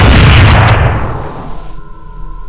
Index of /server/sound/weapons/tfa_cso/elvenranger
fire.wav